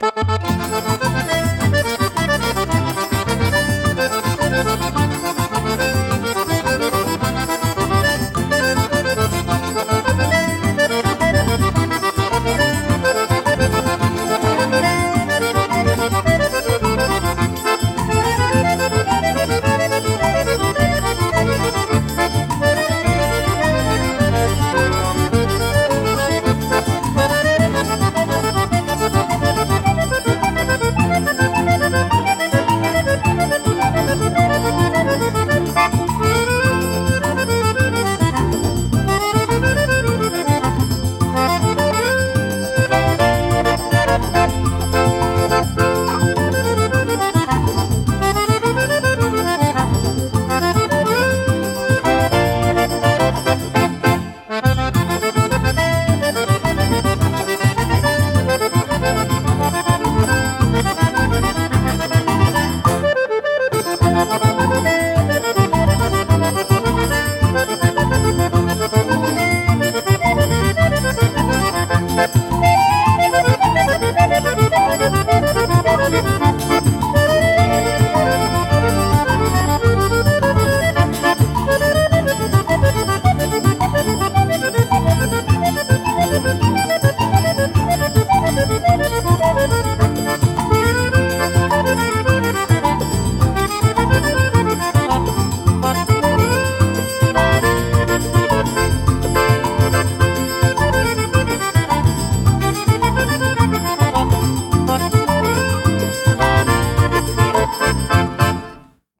instrumental 8